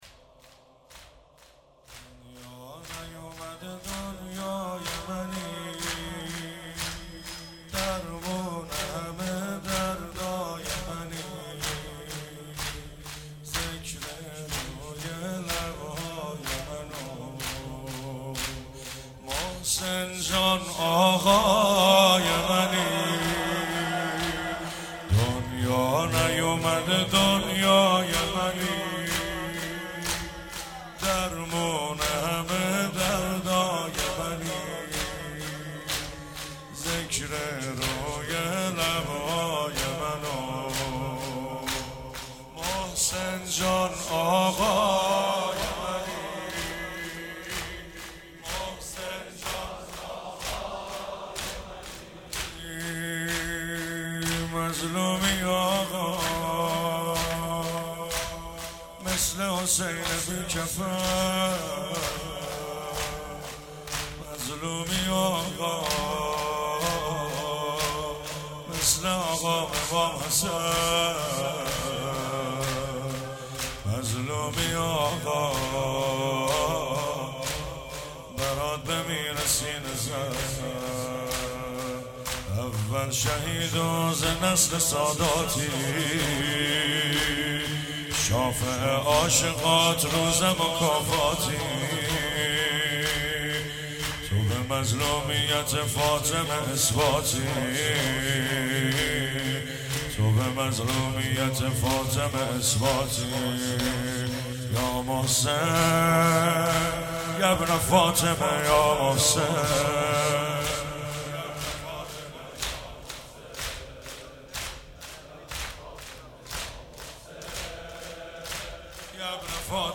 محسنیه 94 - زمینه - دنیا نیومده دنیای منی